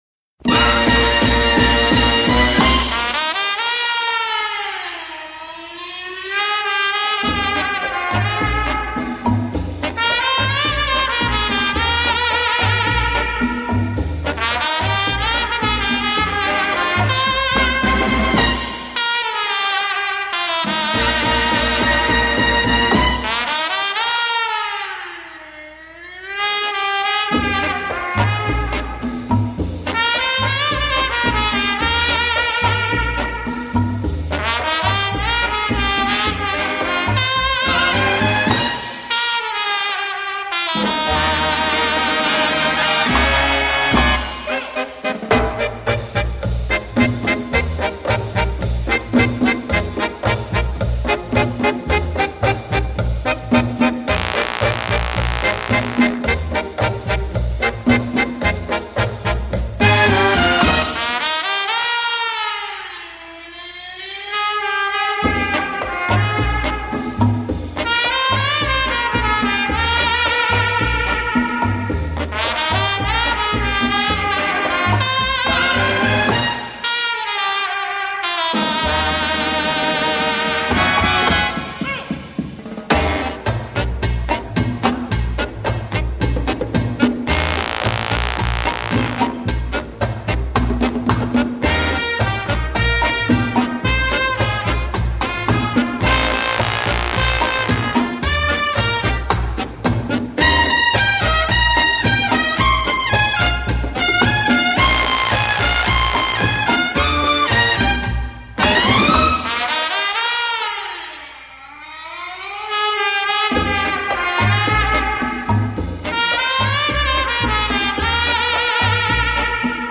Jazz Quality: MP3 VBR V2 kbps 44khz